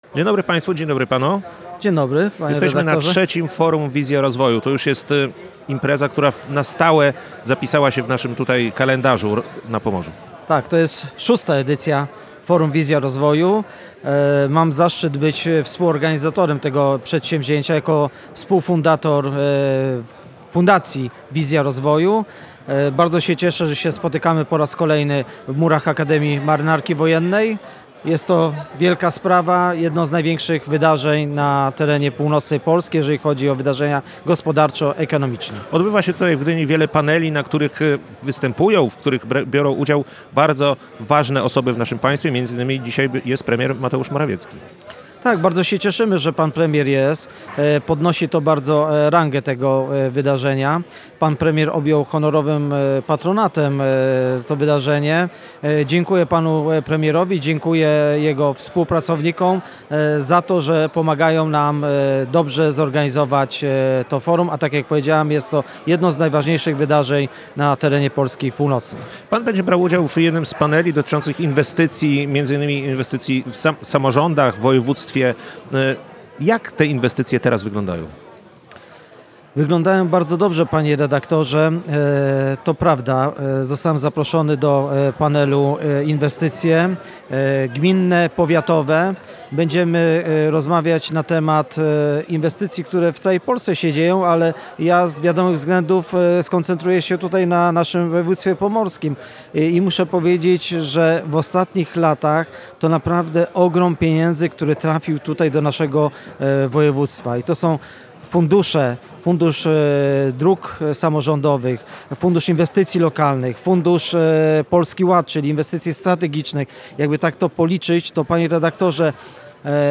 Tylko z Funduszu Dróg Samorządowych, Funduszu Inwestycji Lokalnych czy Polskiego Ładu do naszego województwa trafiły ponad cztery miliardy złotych – podkreślał w Radiu Gdańsk Piotr Karczewski, doradca prezydenta Andrzeja Dudy.